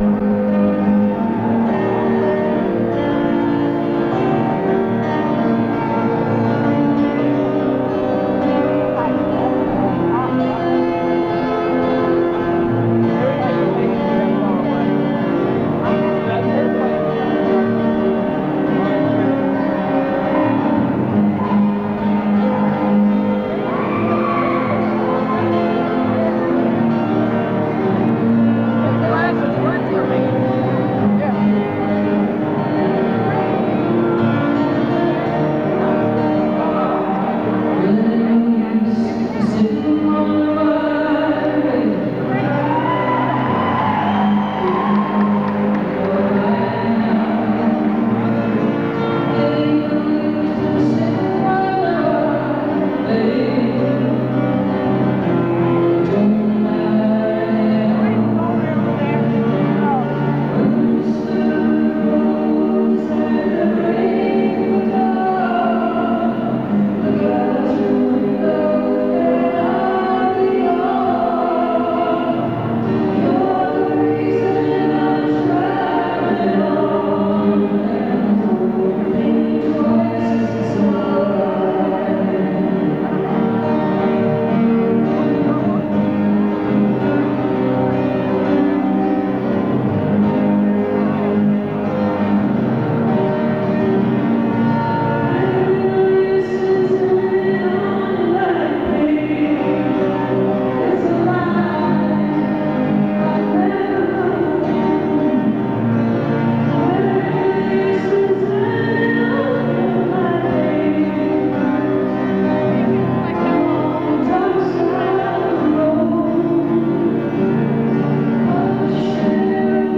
(acoustic duo show)